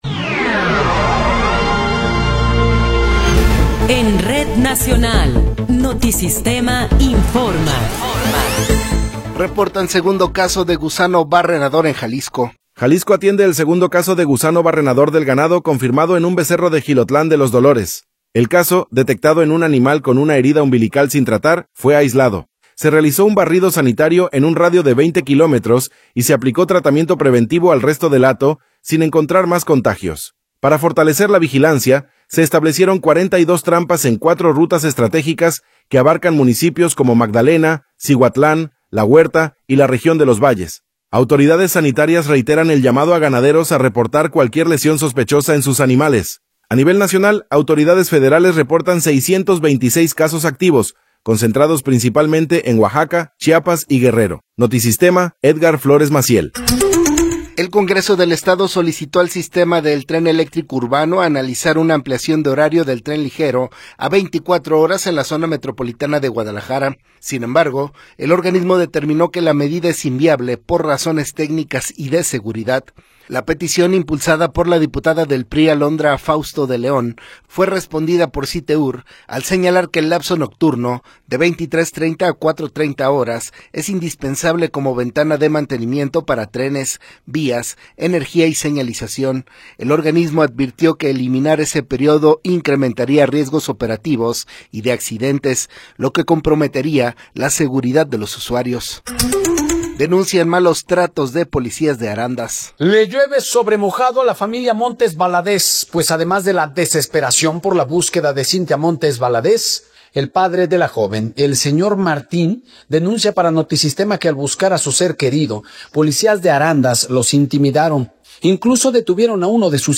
Noticiero 17 hrs. – 9 de Febrero de 2026
Resumen informativo Notisistema, la mejor y más completa información cada hora en la hora.